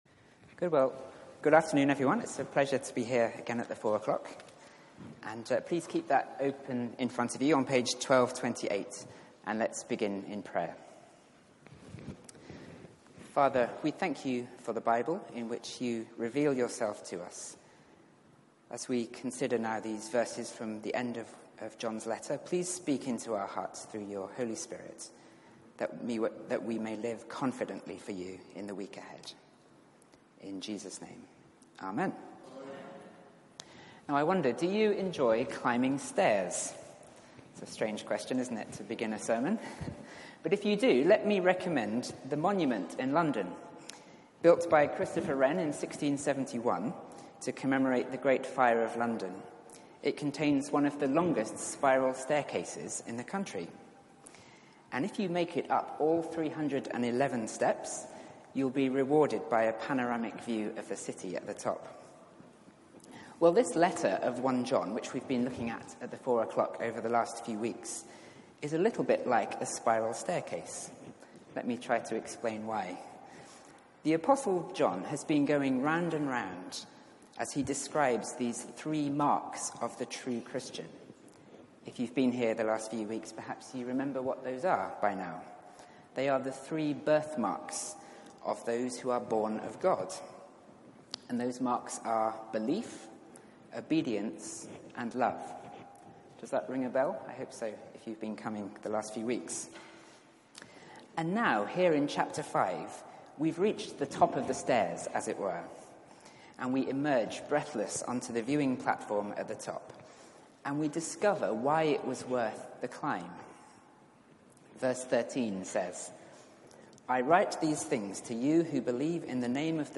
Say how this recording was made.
Media for 4pm Service on Sun 27th May 2018 16:00 Speaker